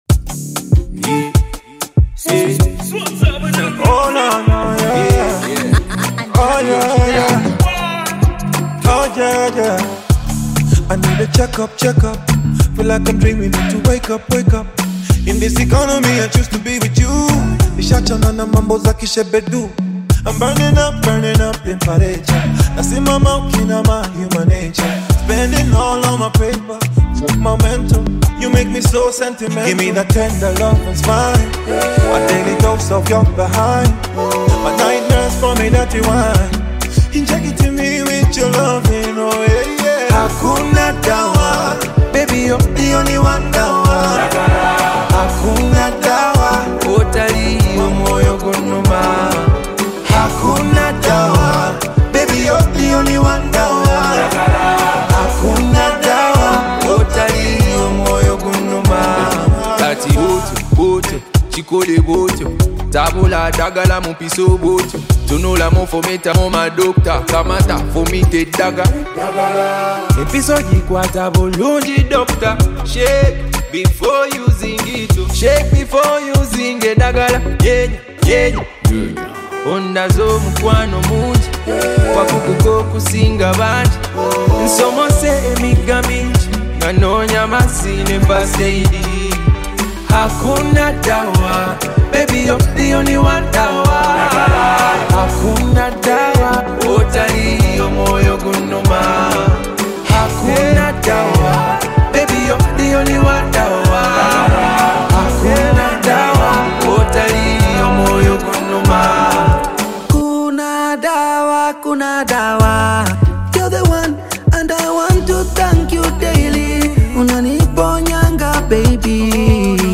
a smooth, soulful blend of Afrobeat and heartfelt emotion.